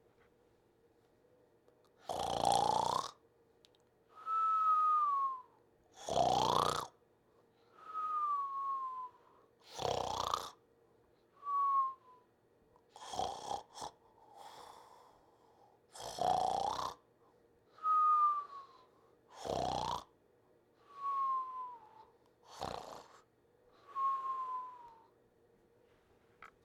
Slow Snore
sleep sleeping slow snore snoring sound effect free sound royalty free Sound Effects